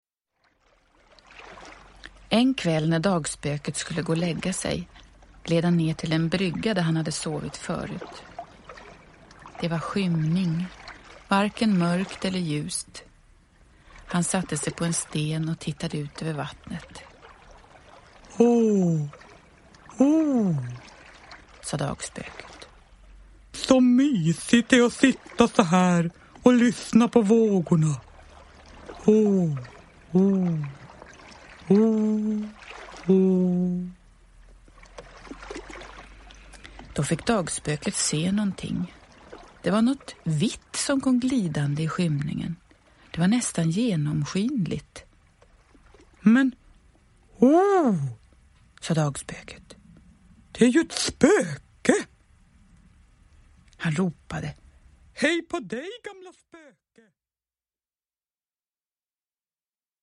Uppläsare: Jujja Wieslander